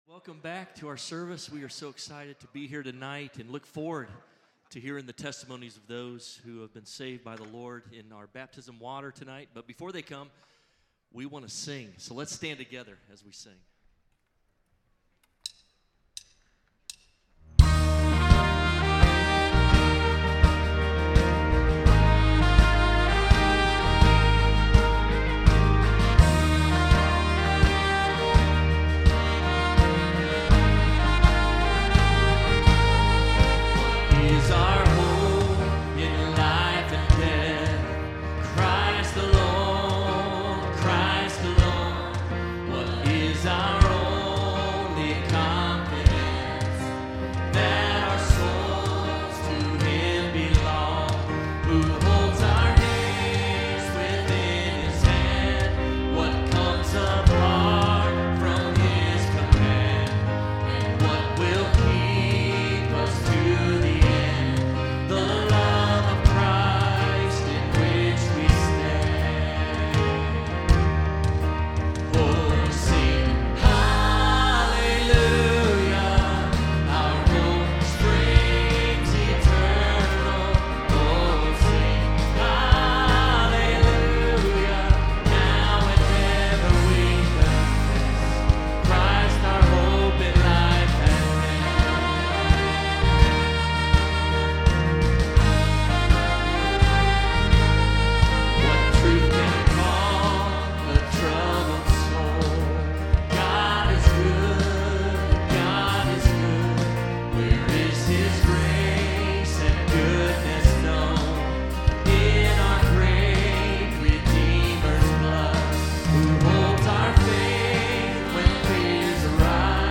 Evening Baptism Service